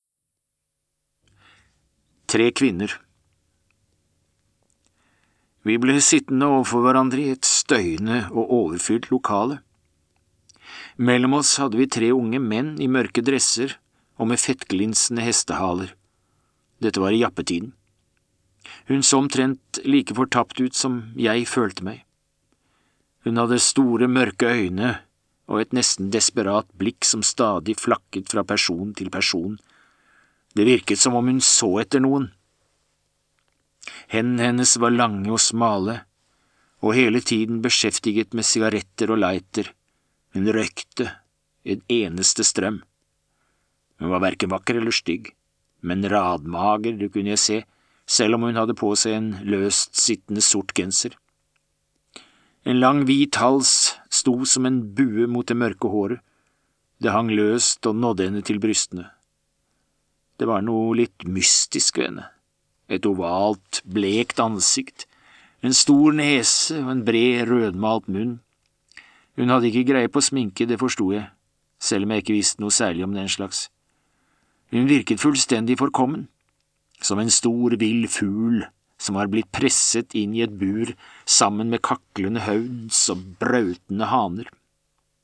Tre kvinner (lydbok) av Ingvar Ambjørnsen